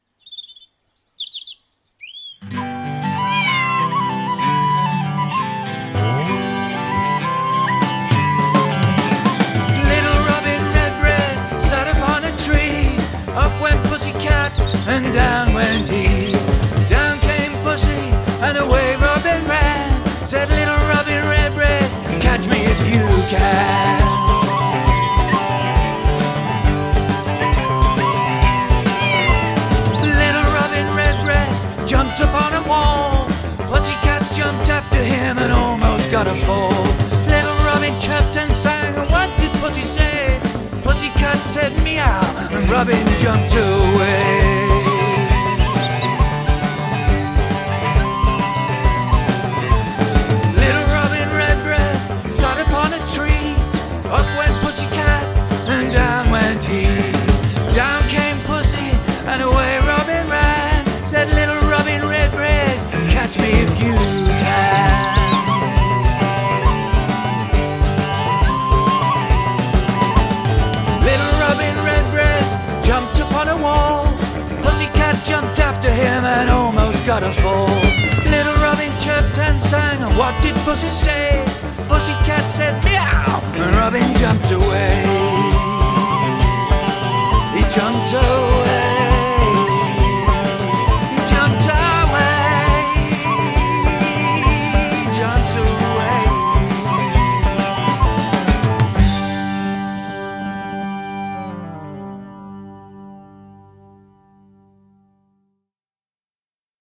TraditionalRock